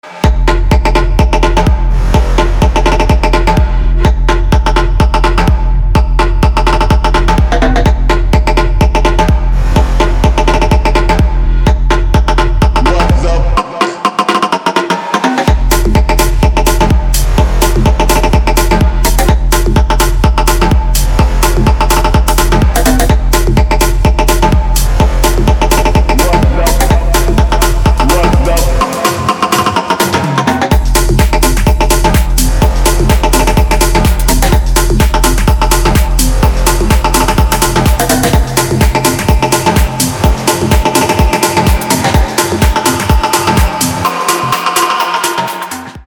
• Качество: 320, Stereo
громкие
Electronic
электронная музыка
future house
Bass
ударные
барабаны